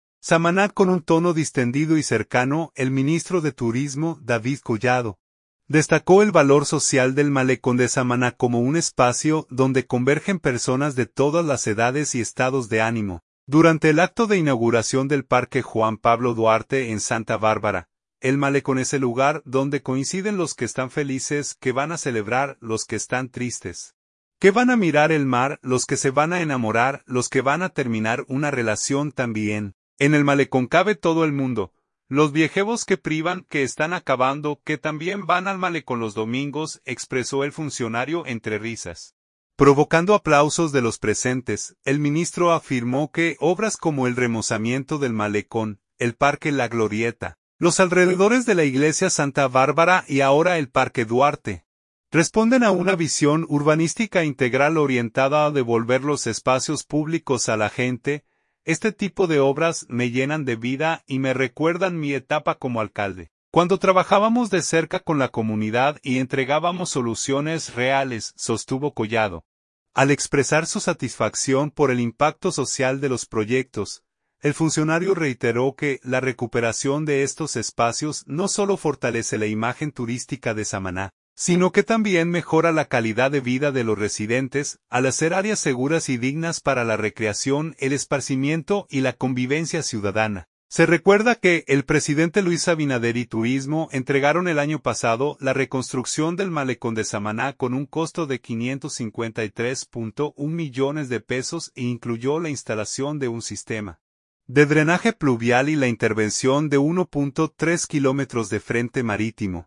Samaná.- Con un tono distendido y cercano, el ministro de Turismo, David Collado, destacó el valor social del malecón de Samaná como un espacio donde convergen personas de todas las edades y estados de ánimo, durante el acto de inauguración del parque Juan Pablo Duarte en Santa Bárbara.